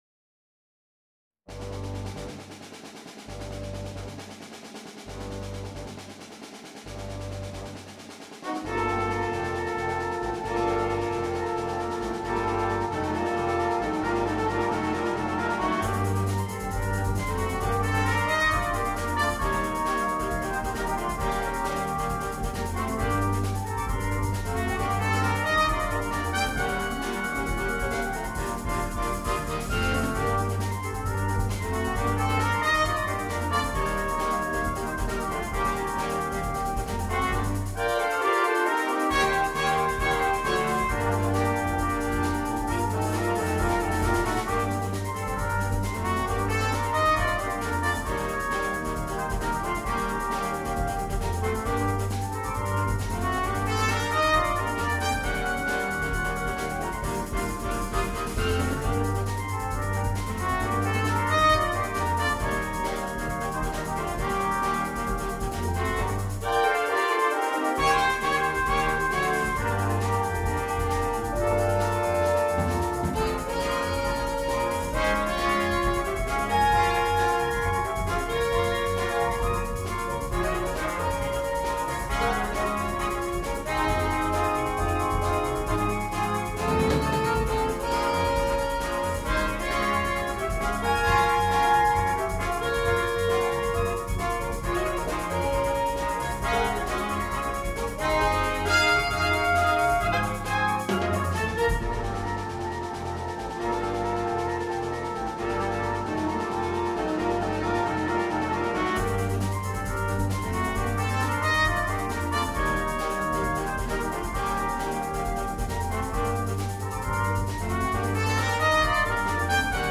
на смешанный состав